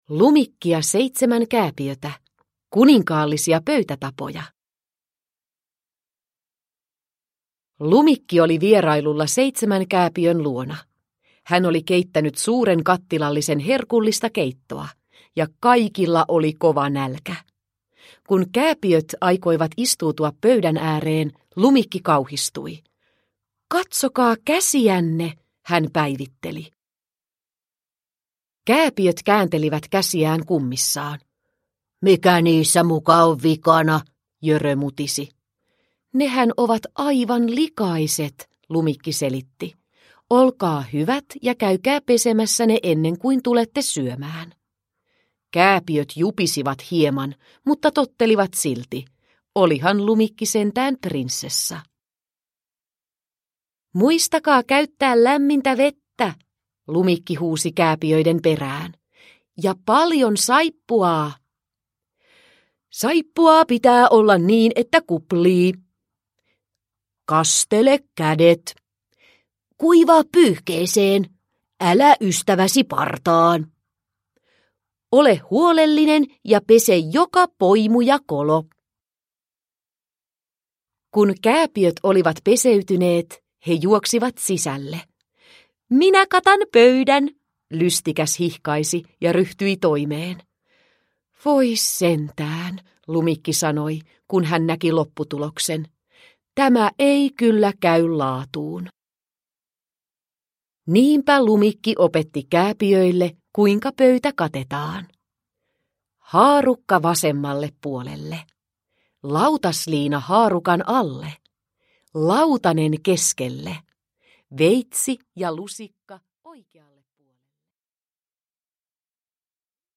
Pienten prinsessojen käytöskirja – Ljudbok – Laddas ner